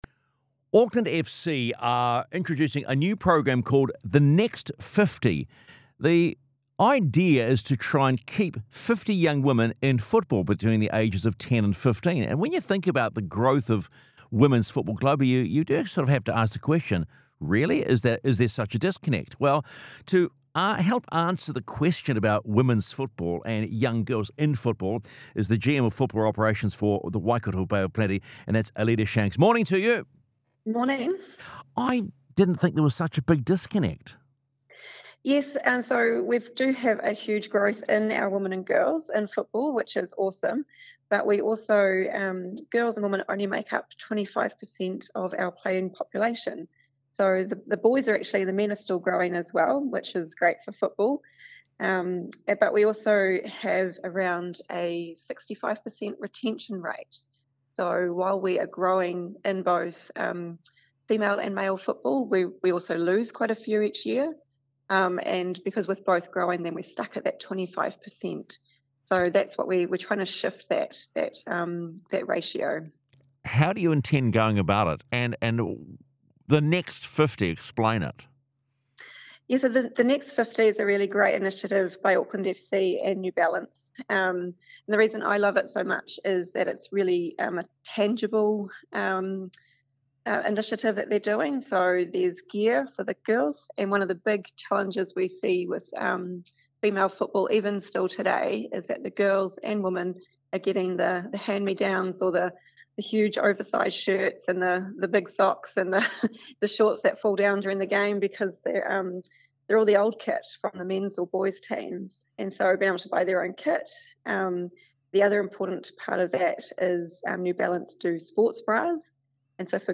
Interview: The history and future of women and girls' football